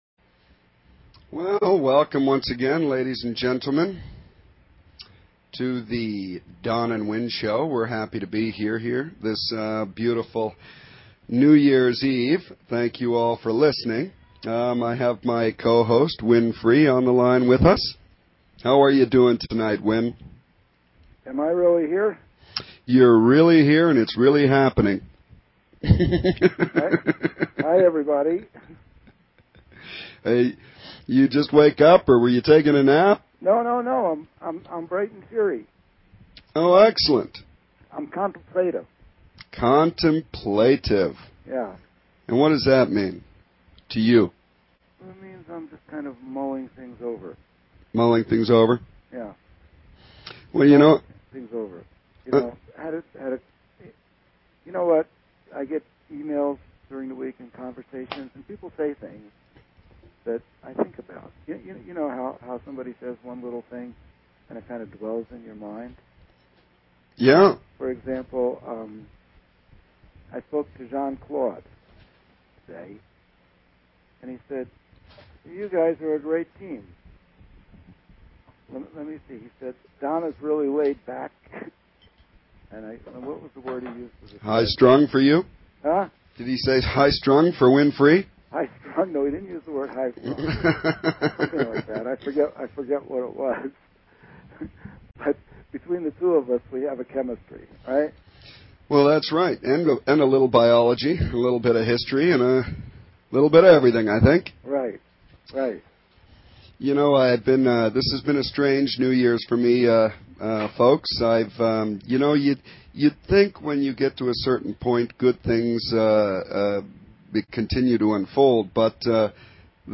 Talk Show Episode
Drive time radio with a metaphysical slant.